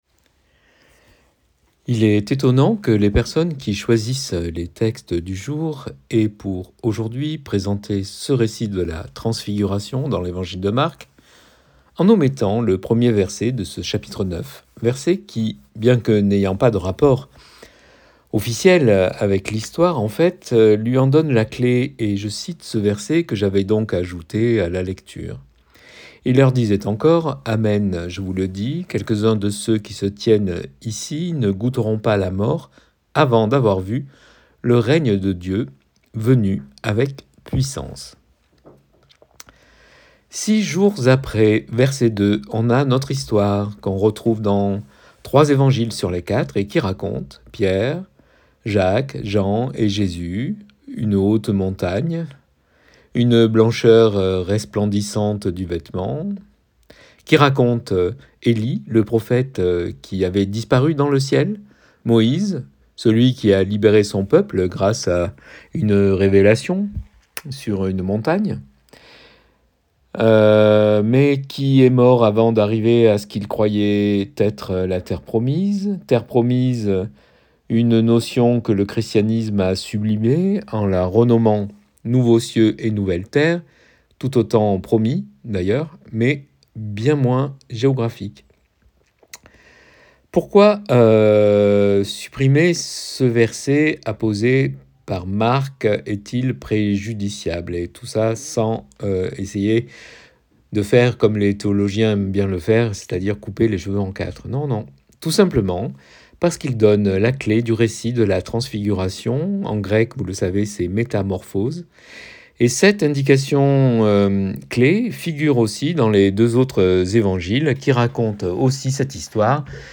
(165.84 Ko) Prédication du 25 février 2024.mp3 (17.78 Mo)